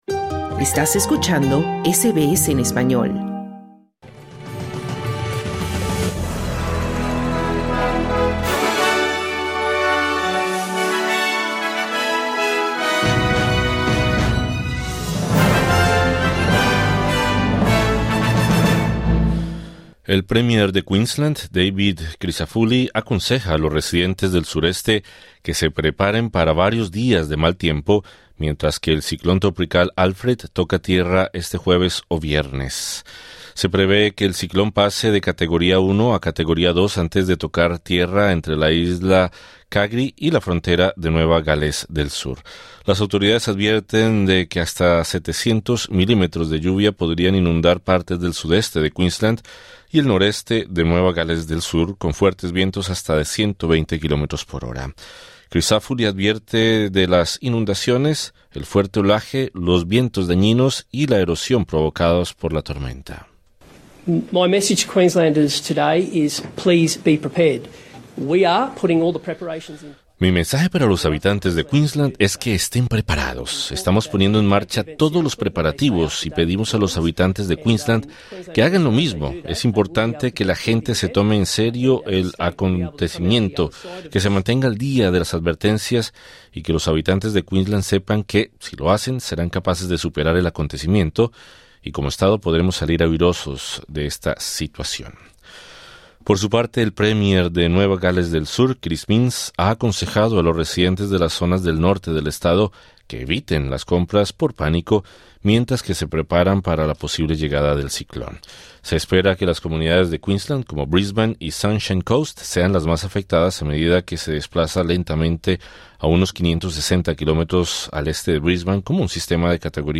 Noticias SBS Spanish | 4 marzo 2025